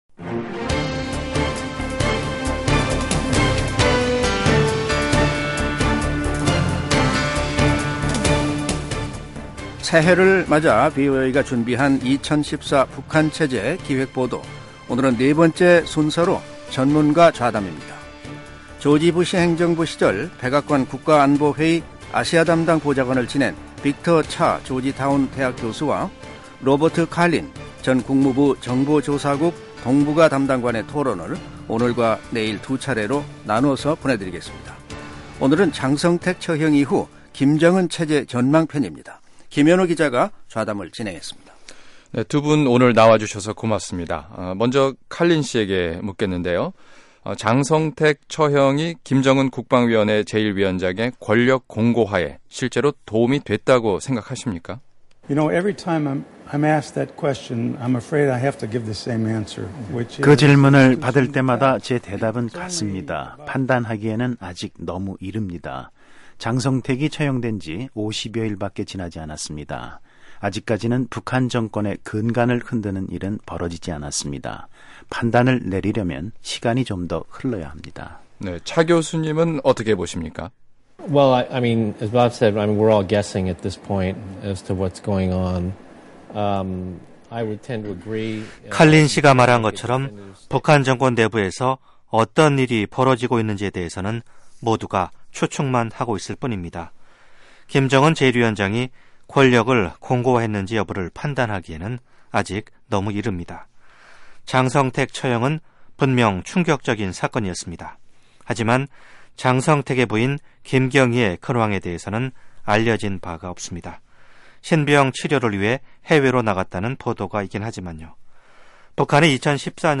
[기획보도: 2014 북한 체제 전망] 4. 미국 전문가 좌담 (1)